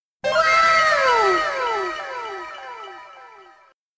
Anime - Wowww!!! Sound Button - Free Download & Play